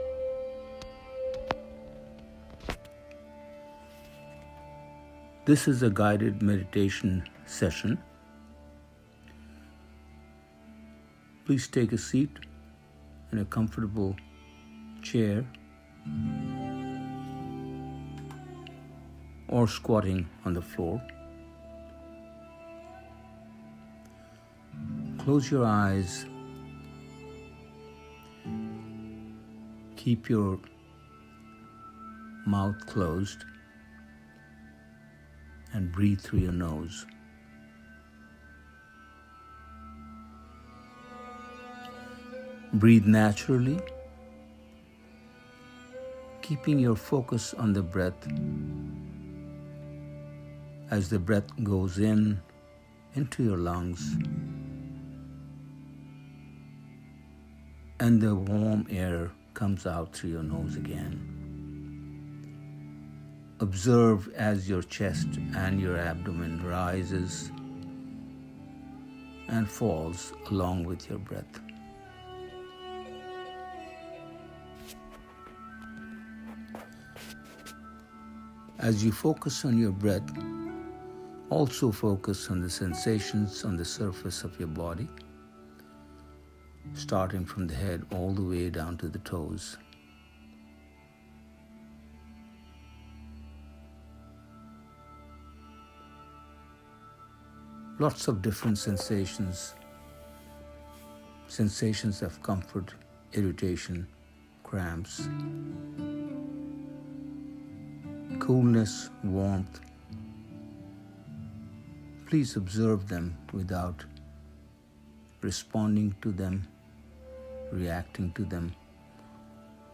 Meditation is the first step in learning to focus on being the best you can be, not only for your own good but also that of the people around you, especially during these trying times. The following link leads to a sample of guided meditation for calming.